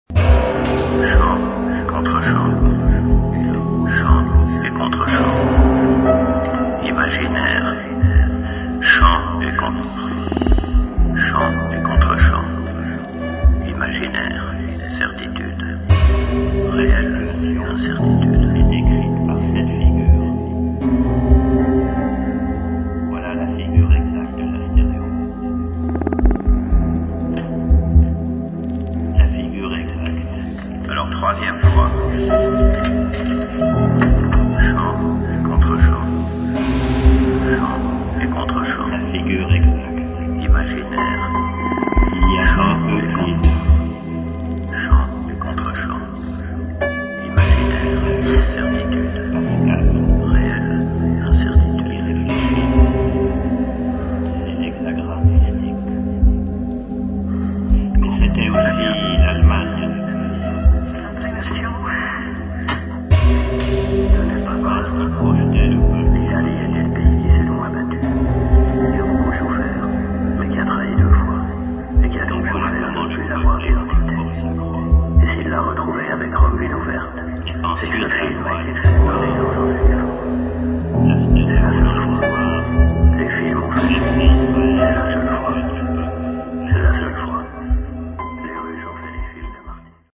[ ELECTRONIC / EXPERIMENTAL / AMBIENT ]